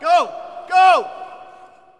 男人说GOGO音效_人物音效音效配乐_免费素材下载_提案神器